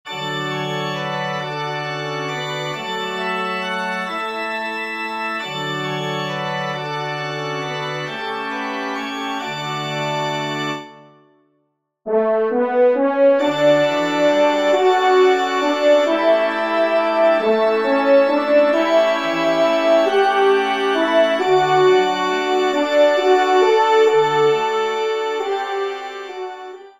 TON DE VÈNERIE   :
ENSEMBLE